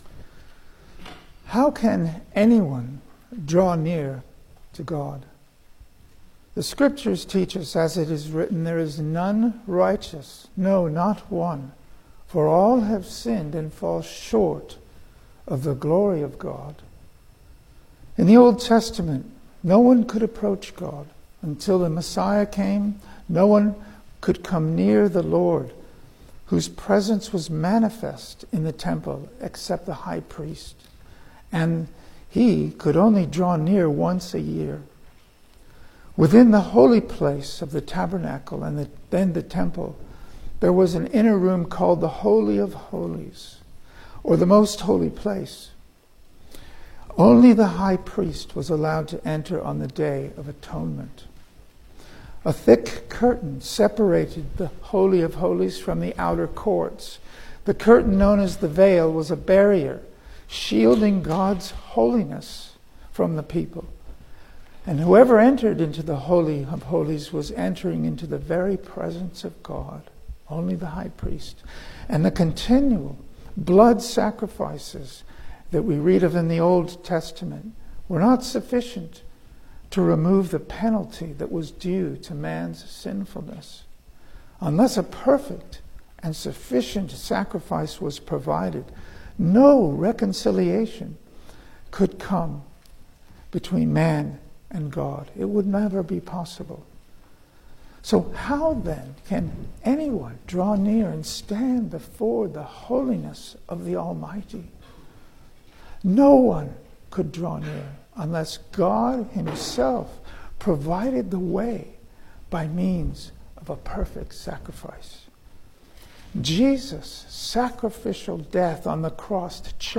Service Type: Sunday Morning
Single Sermons